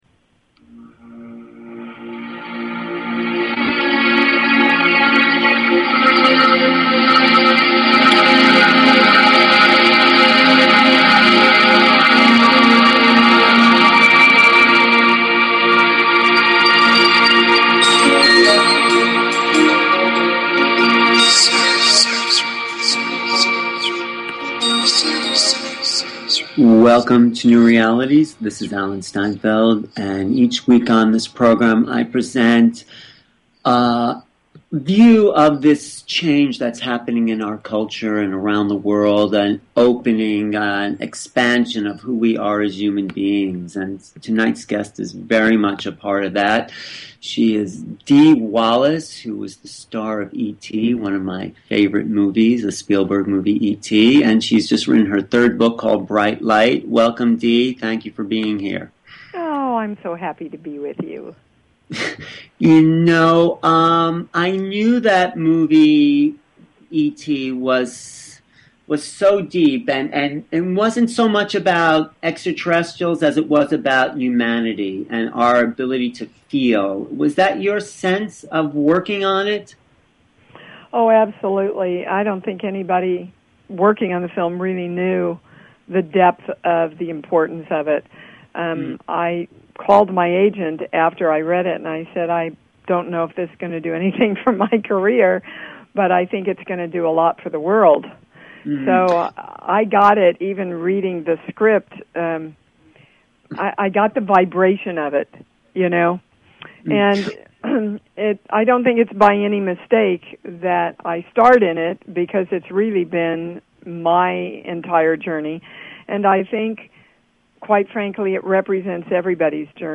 Talk Show Episode, Audio Podcast, New_Realities and Courtesy of BBS Radio on , show guests , about , categorized as
Dee Wallace, the mother in Spielberg's ET, talks about making the movie and her spiritual awakening...